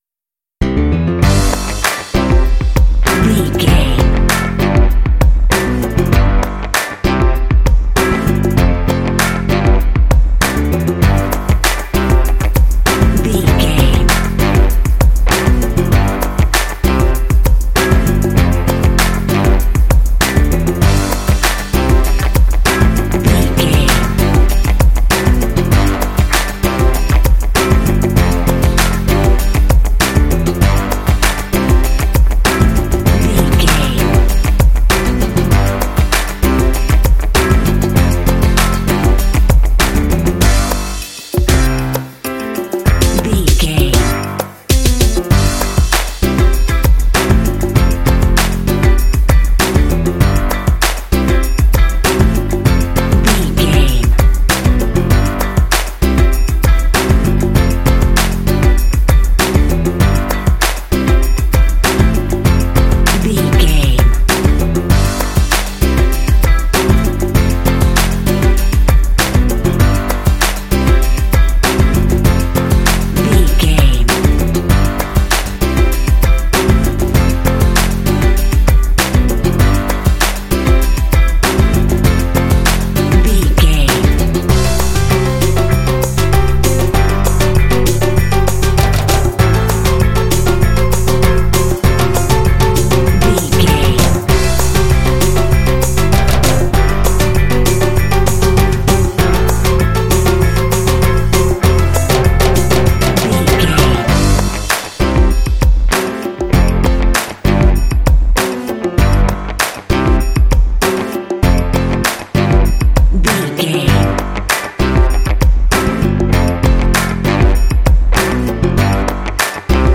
This cute hip hop track is great for kids and family games.
Uplifting
Ionian/Major
bright
happy
bouncy
urban
piano
bass guitar
electric organ
percussion
drums
Funk
underscore